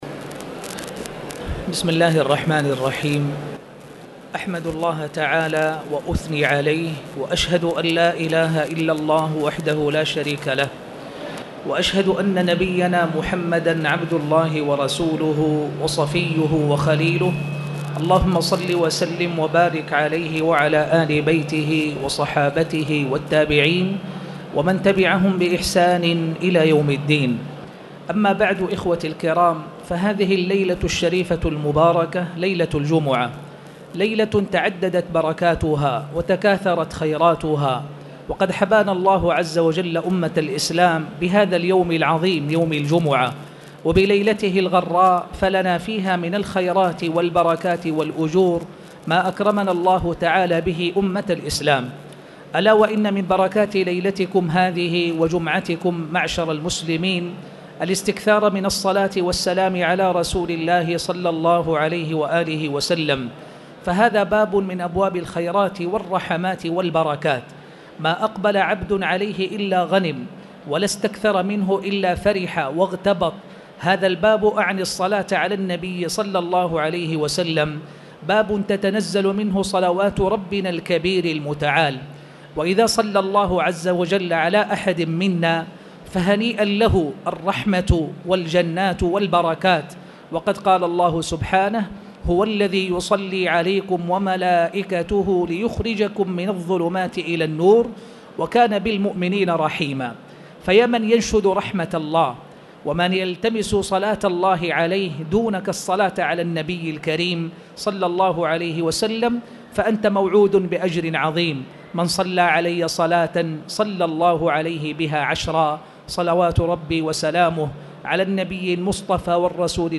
تاريخ النشر ٣٠ ربيع الأول ١٤٣٨ هـ المكان: المسجد الحرام الشيخ